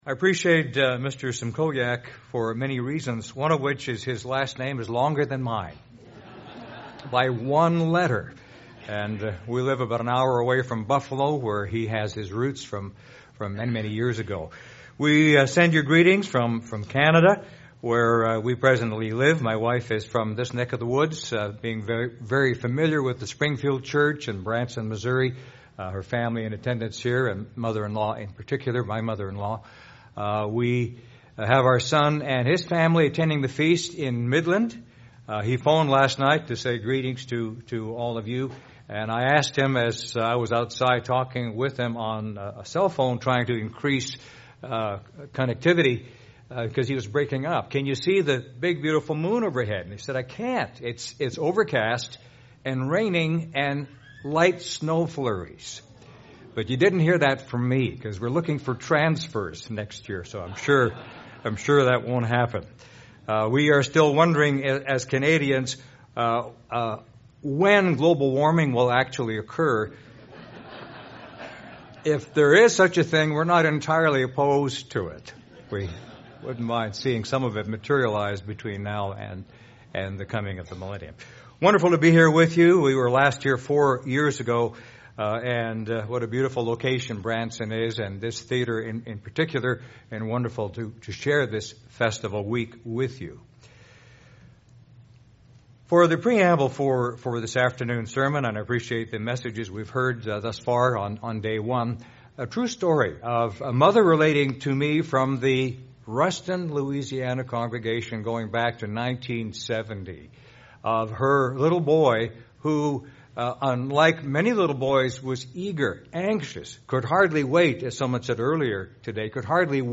This sermon was given at the Branson, Missouri 2019 Feast site.